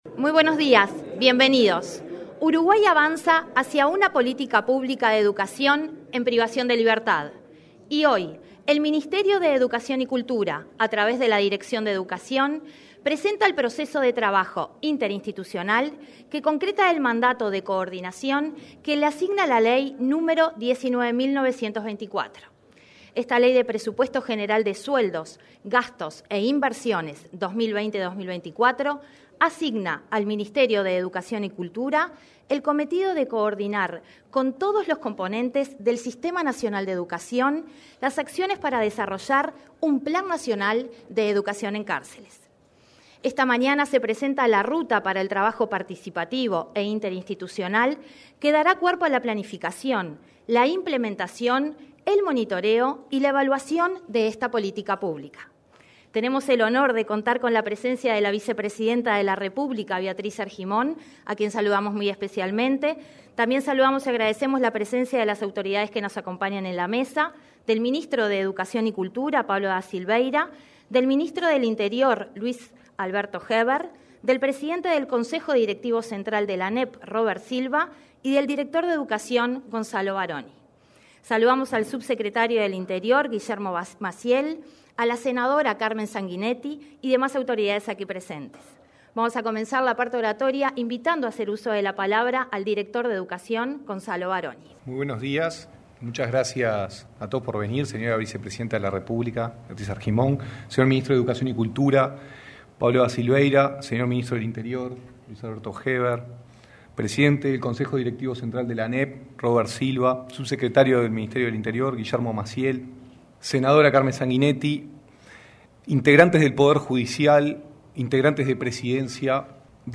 Presentación de Plan Nacional de Educación en Cárceles 19/10/2021 Compartir Facebook X Copiar enlace WhatsApp LinkedIn Este martes 19, participaron en la presentación del Plan Nacional de Educación en Cárceles el director de Educación, Gonzalo Baroni; el presidente del Codicen, Robert Silva; el ministro del Interior, Luis Alberto Heber, y el ministro de Educación y Cultura, Pablo da Silvera.